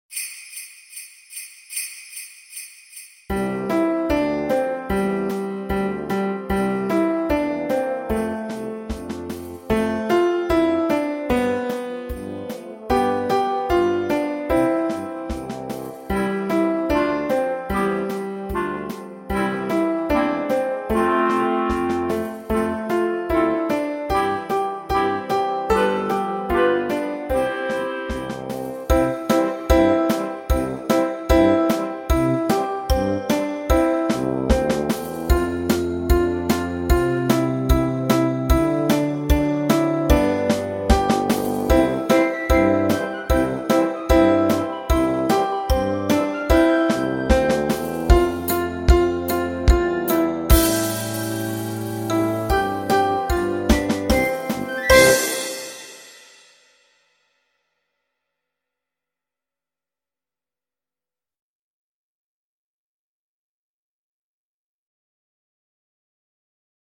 Pr. Accomp